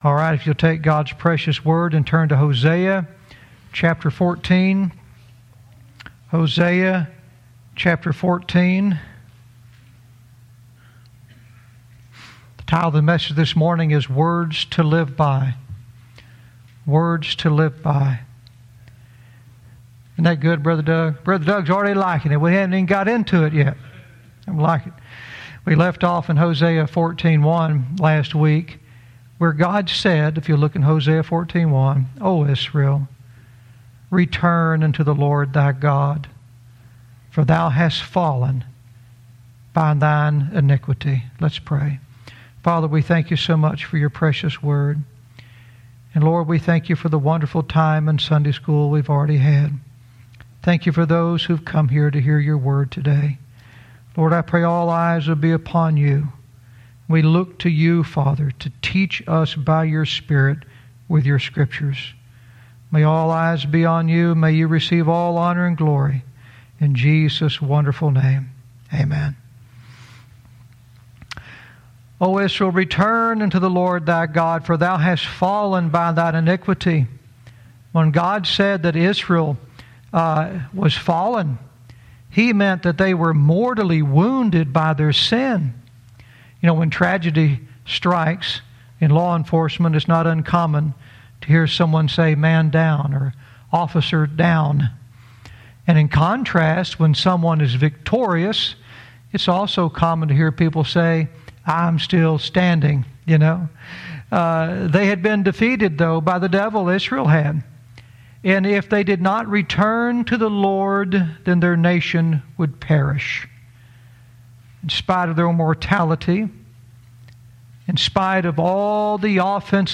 Verse by verse teaching - Hosea 14:2 "Words to Live By"